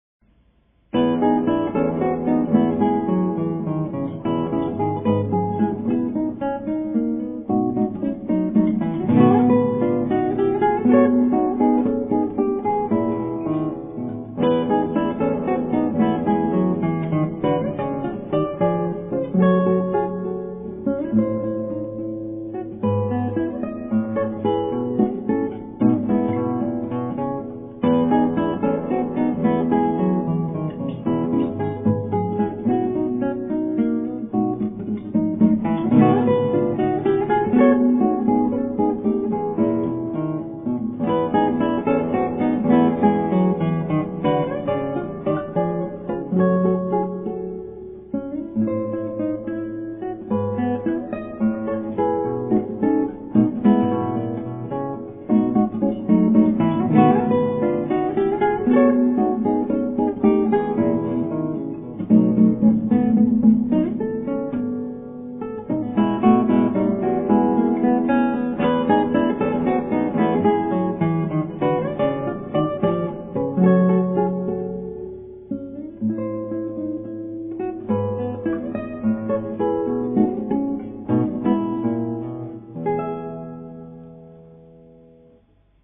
クラシックギター　ストリーミング　コンサート
ちょっと忙しい曲です・・・・じつは結構むずかしいんです。
う～ん、失敗してますねぇ。